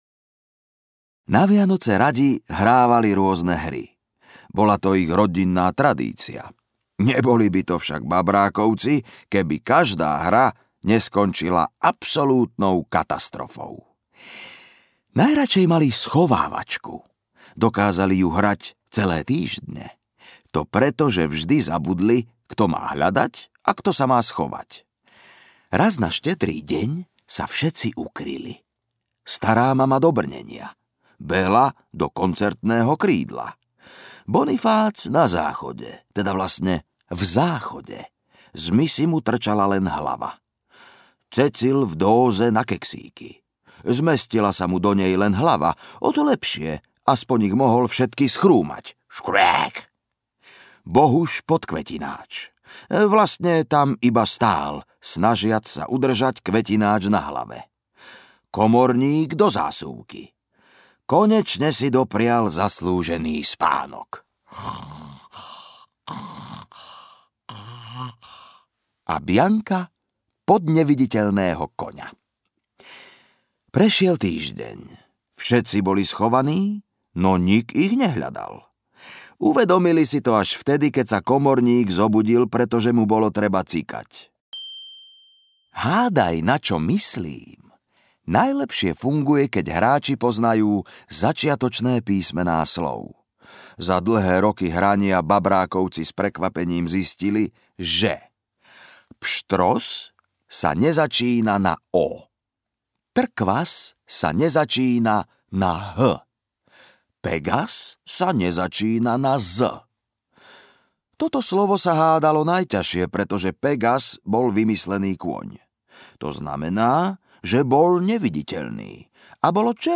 Babrákovci audiokniha
Ukázka z knihy